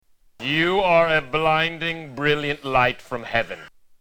Tags: Saturday Night Live Will Ferrell Will Ferrell as James Lipton James Lipton Will Ferrell clips